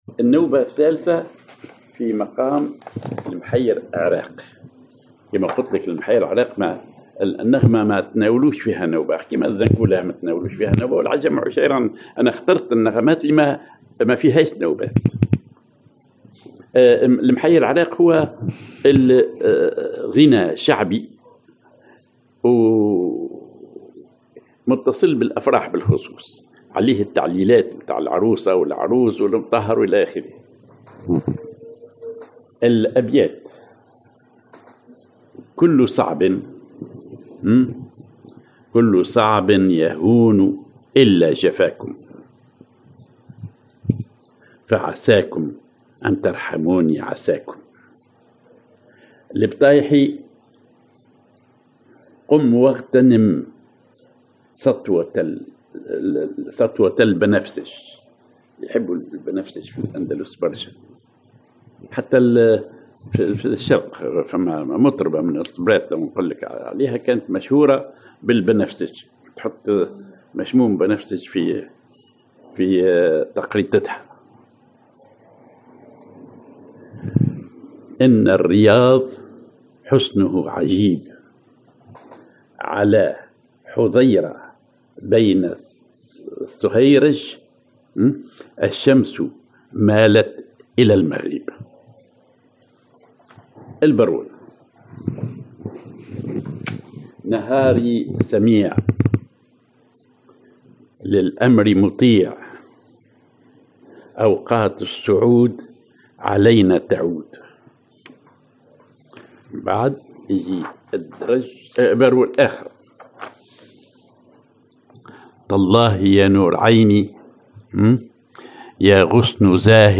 Maqam ar محير عراق
Rhythm ar إيقاعات النوبة التقليدية التونسية
genre نوبة